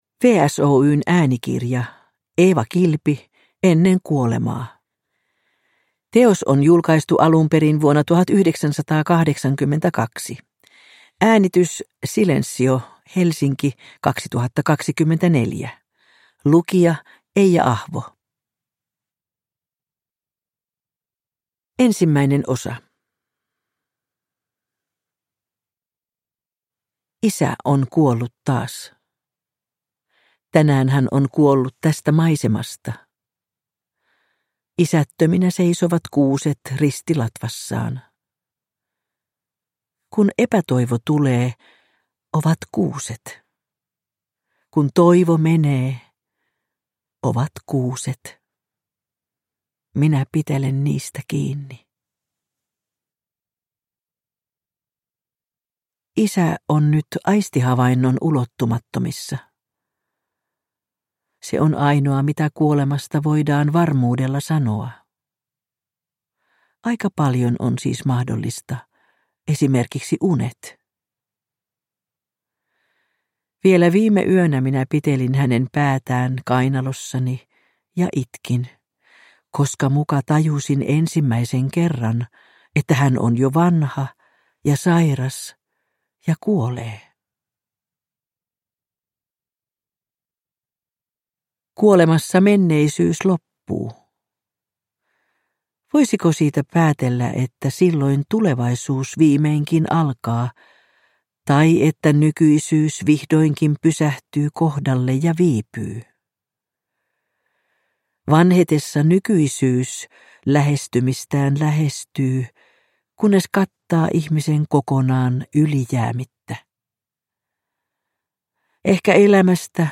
Ennen kuolemaa – Ljudbok
Uppläsare: Eija Ahvo